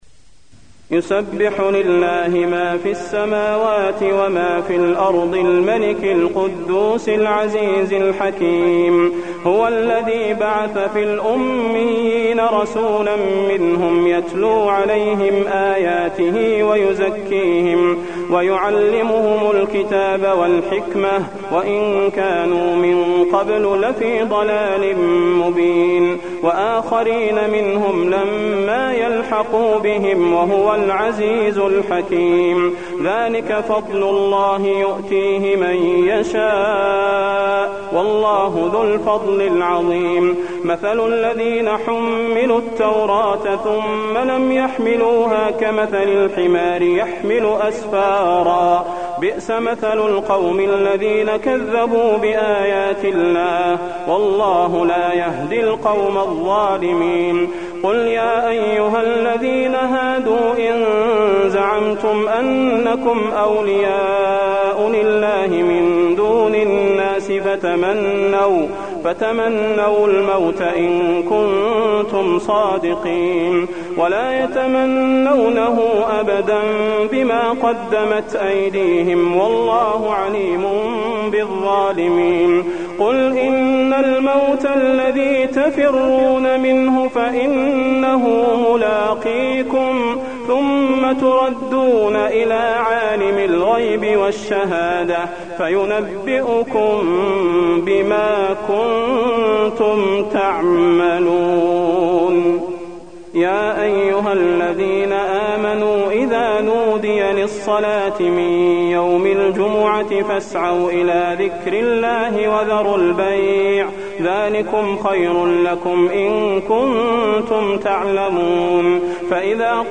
تلاوة سورة الجمعة
المكان: المسجد النبوي الشيخ: فضيلة الشيخ د. صلاح بن محمد البدير فضيلة الشيخ د. صلاح بن محمد البدير سورة الجمعة